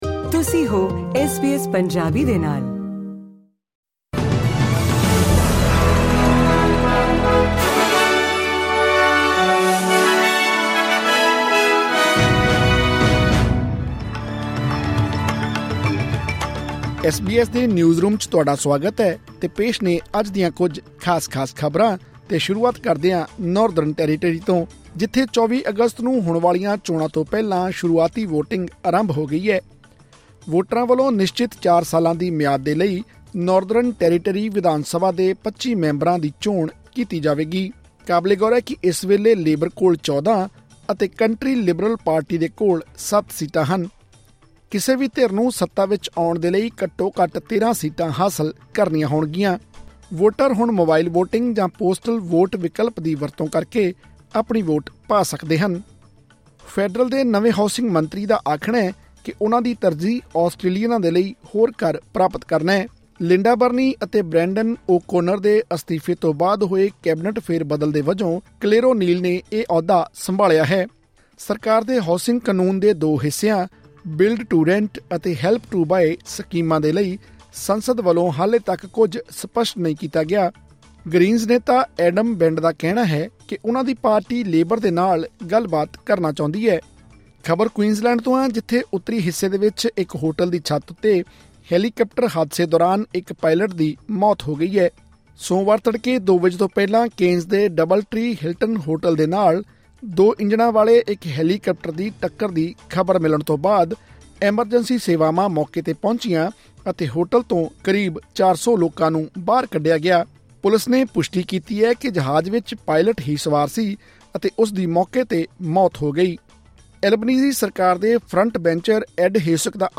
ਐਸ ਬੀ ਐਸ ਪੰਜਾਬੀ ਤੋਂ ਆਸਟ੍ਰੇਲੀਆ ਦੀਆਂ ਮੁੱਖ ਖ਼ਬਰਾਂ: 12 ਅਗਸਤ 2024